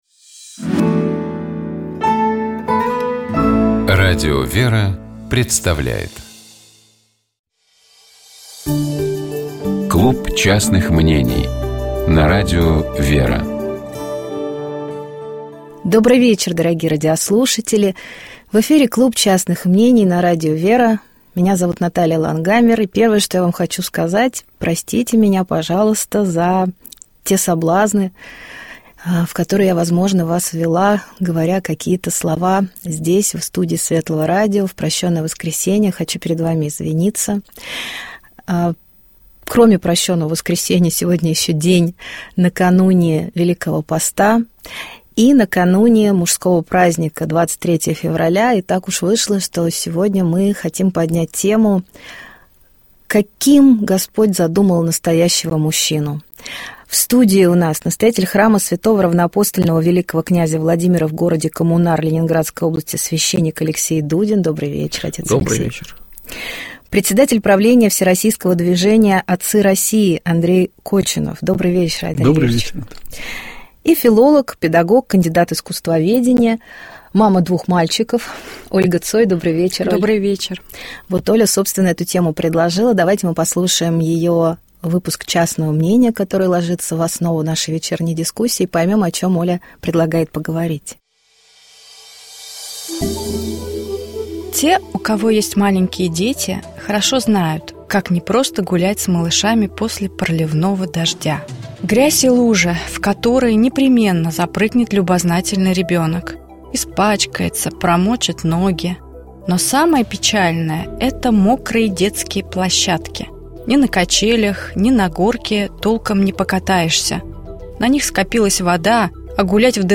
Программа «Светлый вечер» — это душевная беседа ведущих и гостей в студии Радио ВЕРА. Разговор идет не о событиях, а о людях и смыслах.